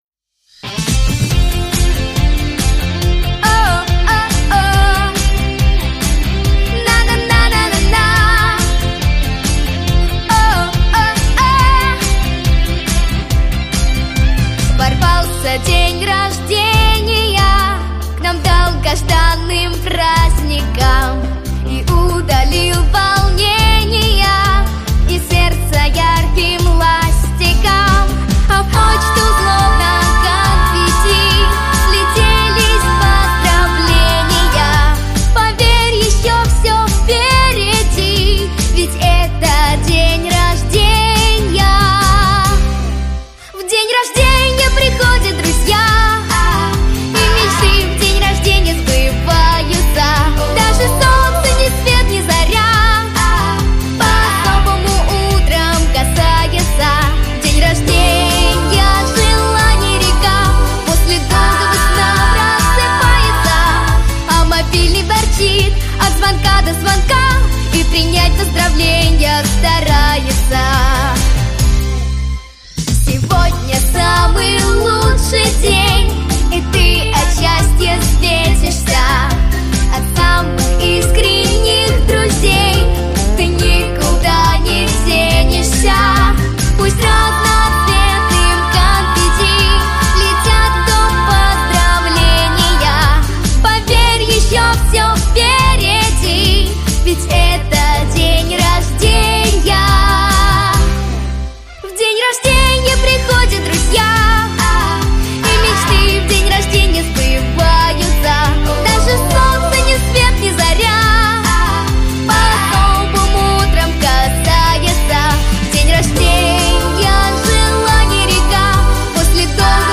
песня.